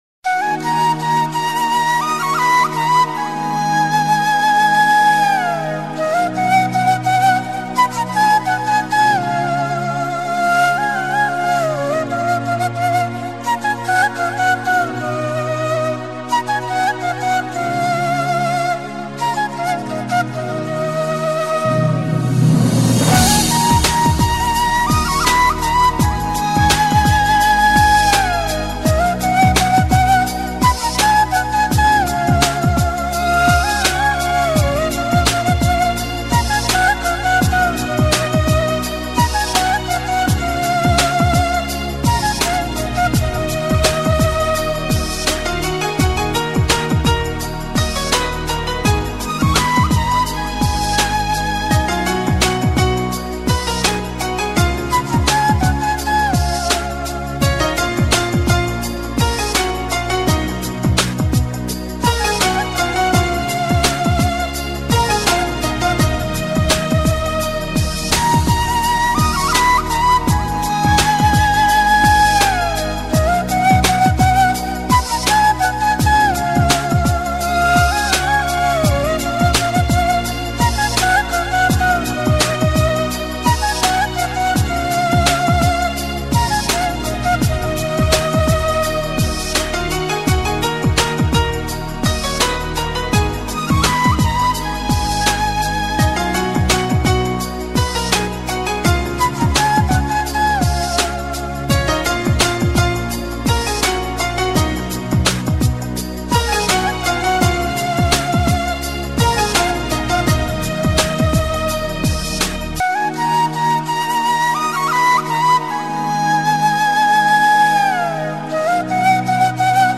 indeyskaya_etnicheskaya_muzyka_fleyta.mp3